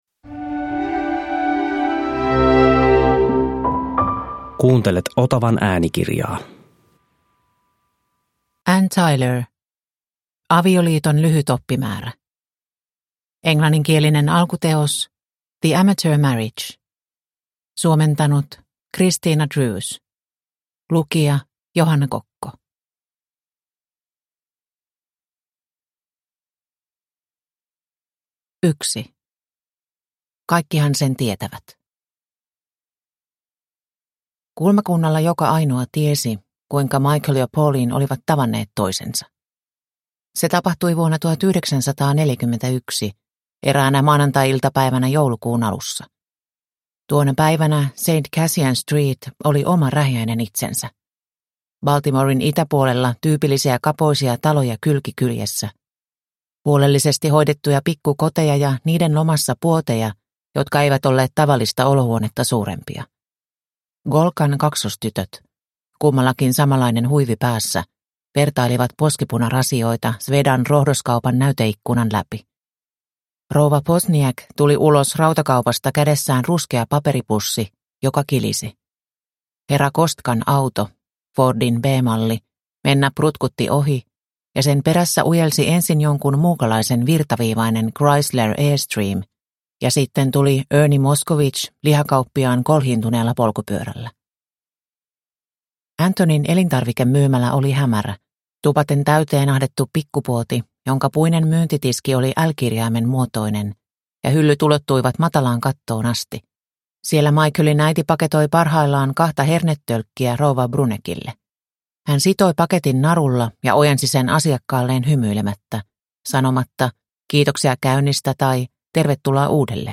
Avioliiton lyhyt oppimäärä – Ljudbok – Laddas ner